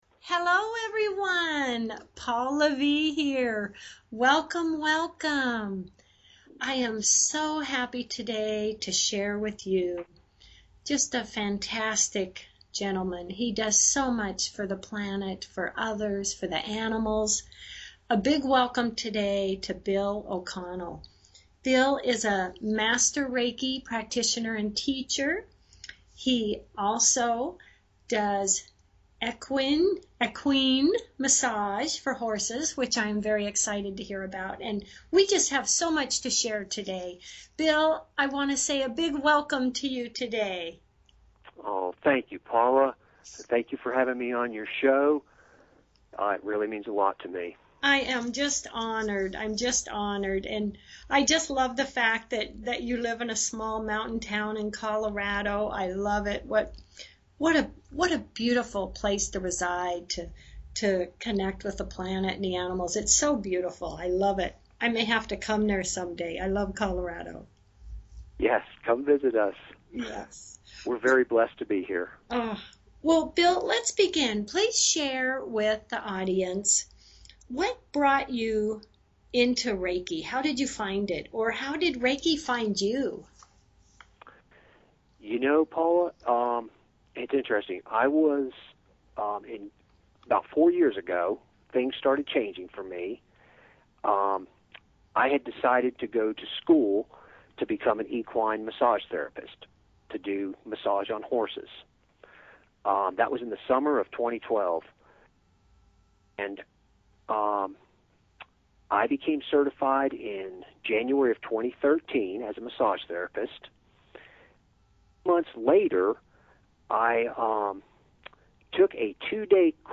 Talk Show Episode, Audio Podcast